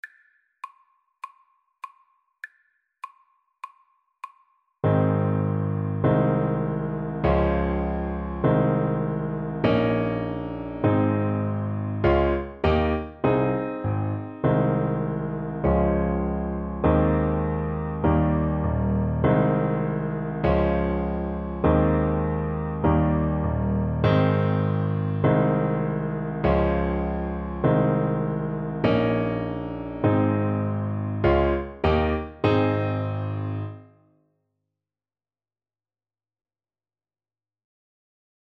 Alto SaxophonePiano
Moderato
4/4 (View more 4/4 Music)
Saxophone  (View more Beginners Saxophone Music)